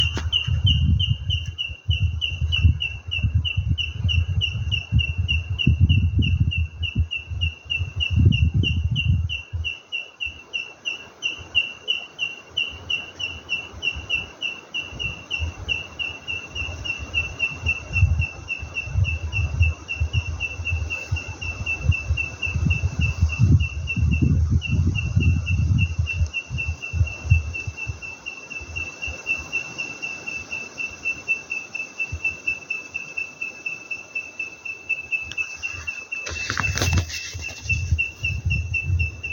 Nombre científico: Cormobates leucophaea
Nombre en inglés: White-throated Treecreeper
Localidad o área protegida: Lamington National Park
Condición: Silvestre
Certeza: Vocalización Grabada
white-throated-treecreeper.mp3